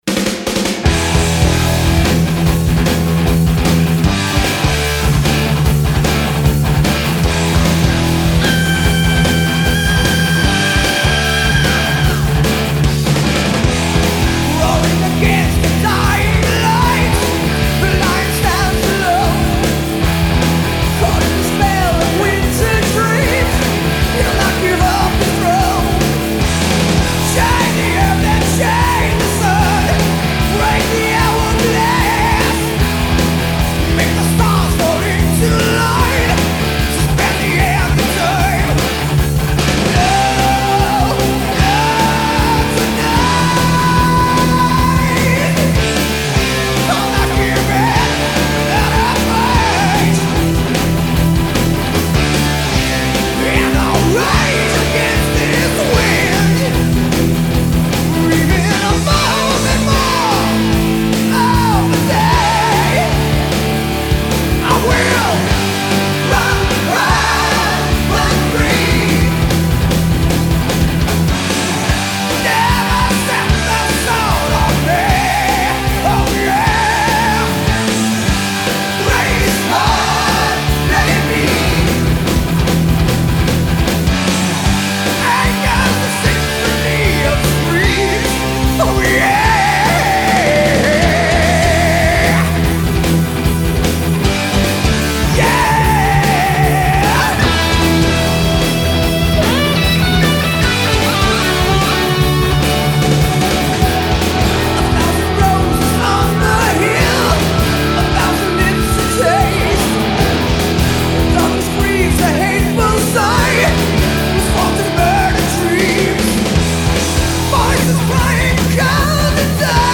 Genre, Hard Rock, Heavy Metal, Song Reviews